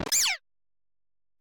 Fichier:Cri 0906 EV.ogg — Poképédia
Cri de Poussacha dans Pokémon Écarlate et Violet.